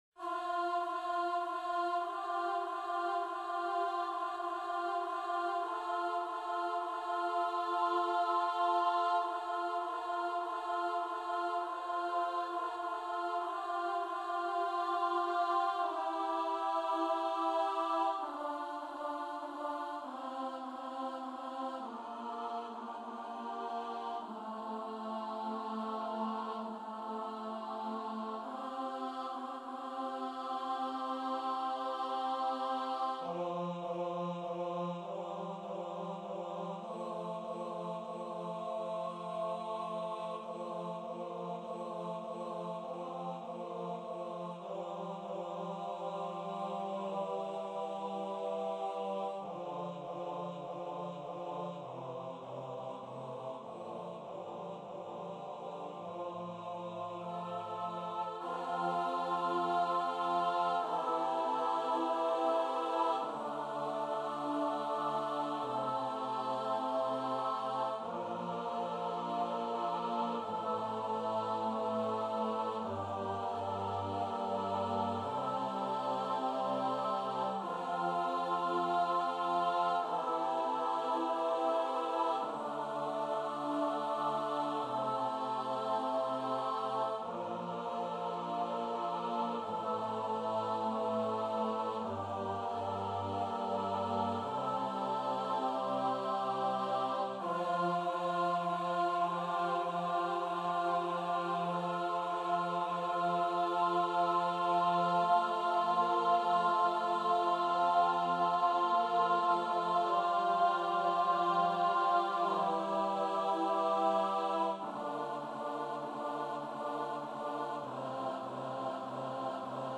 - Œuvre pour choeur à 8 voix mixtes (SSAATTBB) a capella
MP3 rendu voix synth.
Alto 2